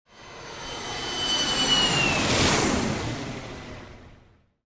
5Jet Plane.WAV